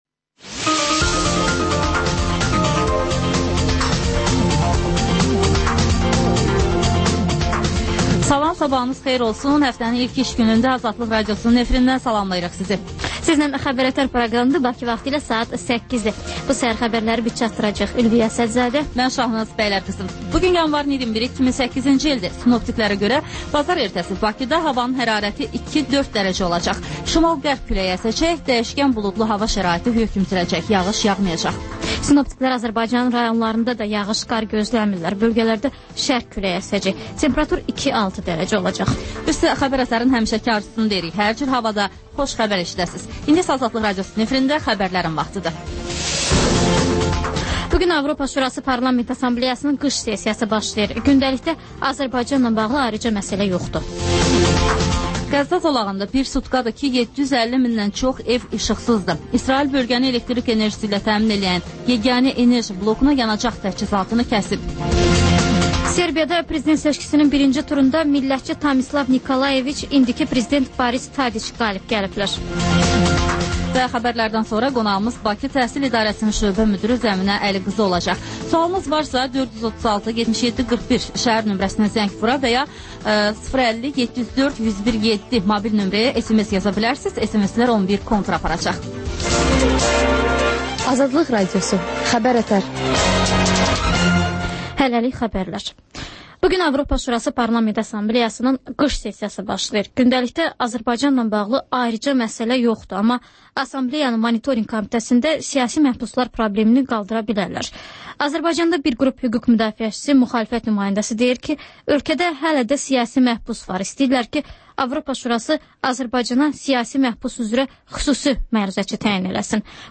Xəbər-ətər: xəbərlər, müsahibələr və İZ: mədəniyyət proqramı